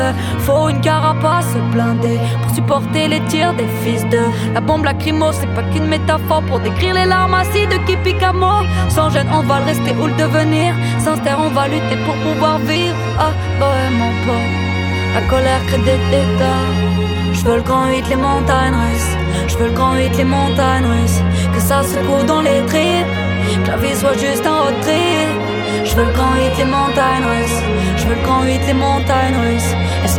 Rap francophone